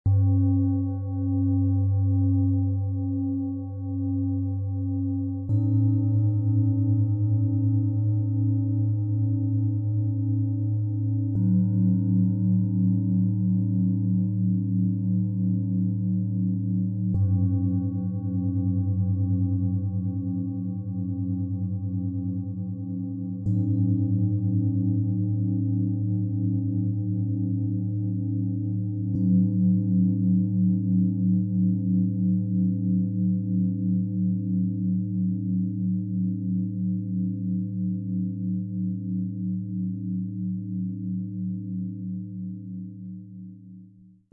Drei kraftvolle Töne verbinden sich zu einer harmonischen Einheit, die Energie stärkt, Inspiration öffnet und Wachstum liebevoll begleitet.
Im Sound-Player - Jetzt reinhören kann der Original-Ton dieser sorgfältig ausgewählten Klangschalen angehört werden.
Tiefster Ton: Tageston, Biorhythmus Körper, Delfin
Bengalen-Schale, matt, Durchmesser 24,6 cm, Höhe 9,4 cm
Mittlerer Ton: Neptun
Höchster Ton: Jupiter, Delfin, Mond